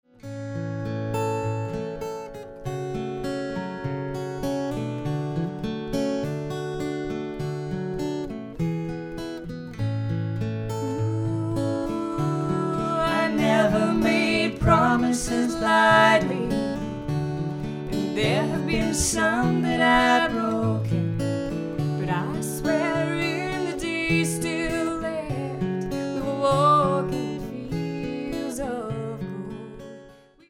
Showcase Day at Port Glasgow Town Hall